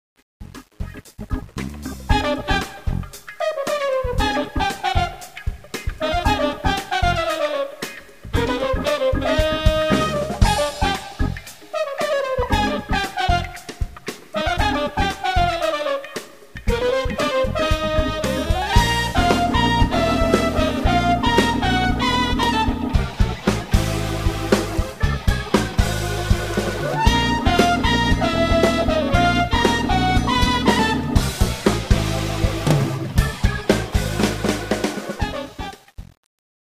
Recorded at Sanctuary Studios, Broadalbin, NY 2004-2011